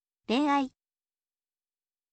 ren ai